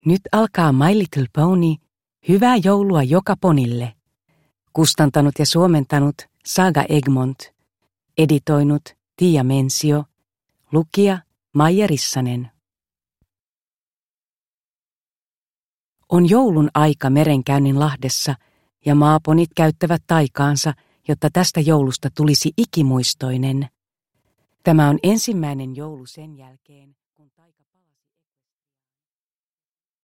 My Little Pony - Hyvää joulua joka ponille! – Ljudbok